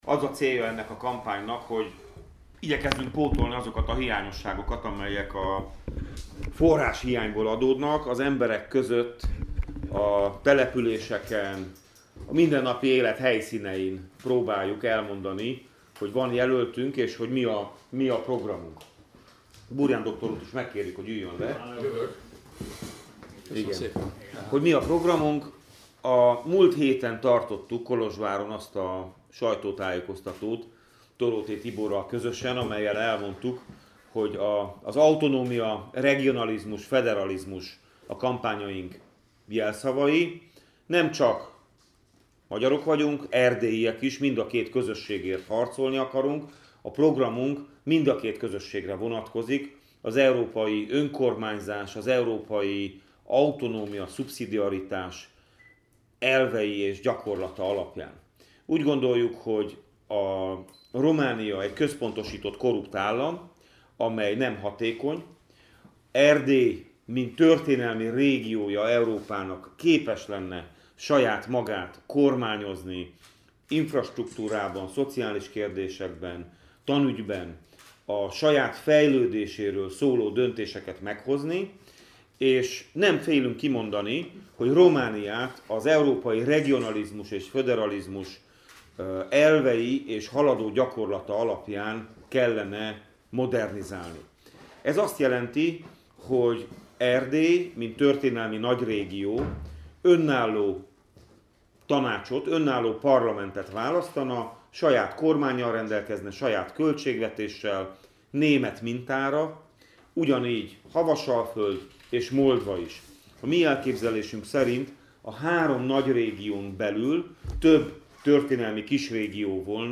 Szilágyi Zsolt aradi kortesbeszédét teljes terjedelmében meghallgathatja itt.
Szilagyi_Zsolt_allamelnokjelolti_kortesbeszed.mp3